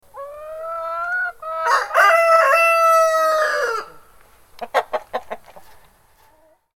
Chickens-and-rooster-in-a-chicken-coop-sound-effect.mp3